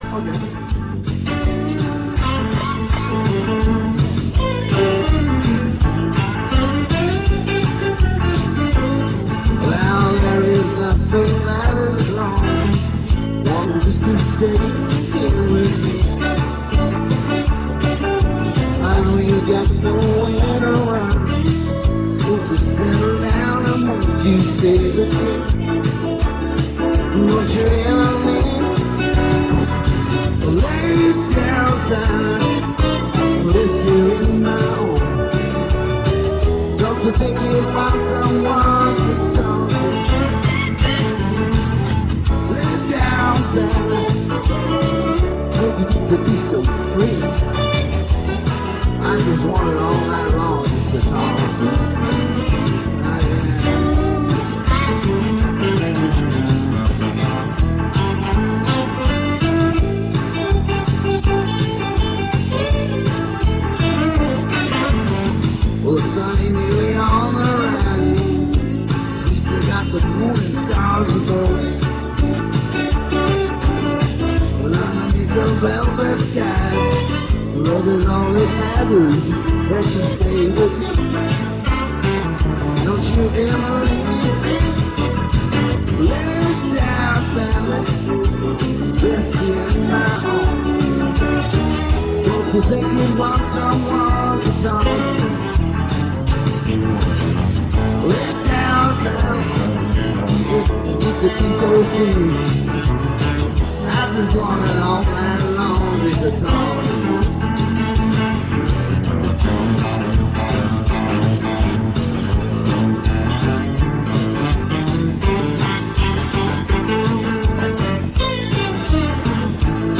* * *Country Rock* * * live guitar and vocals